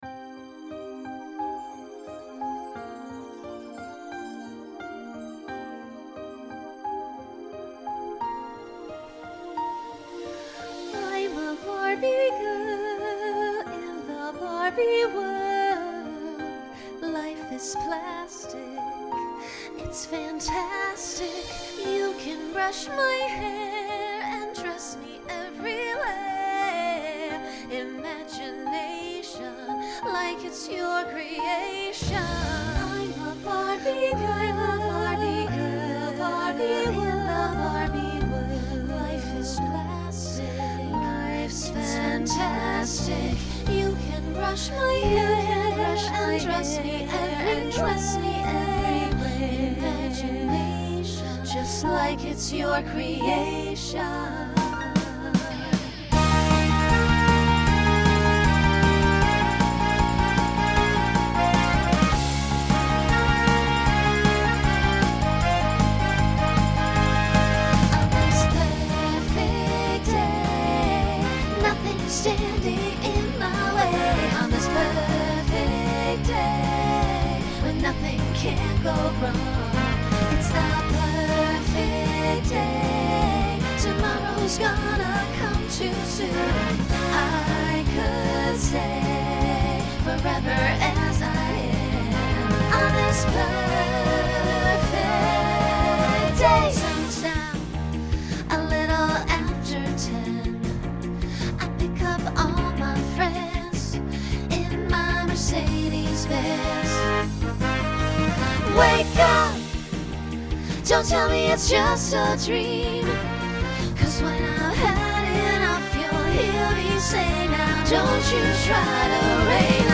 Genre Pop/Dance
Show Function Opener Voicing SSA